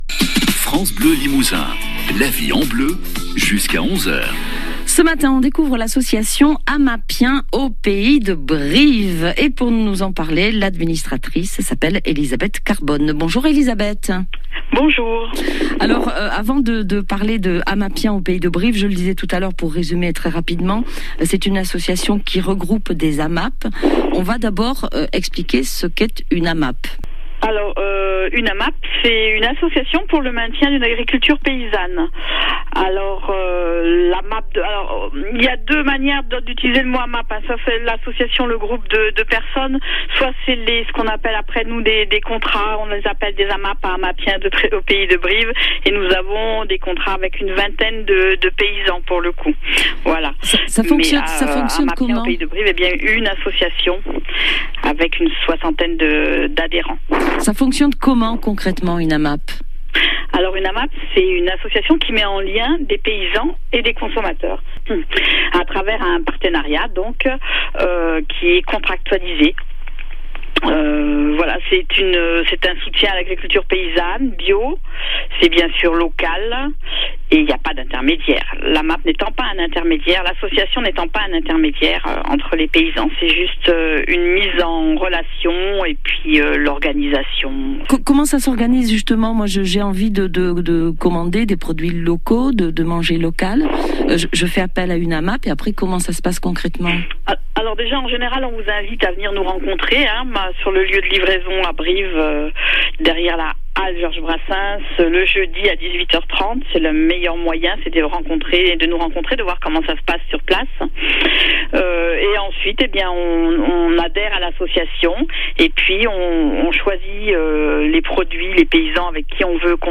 INTERVIEW FRANCE BLEU LIMOUSIN JANVIER 2021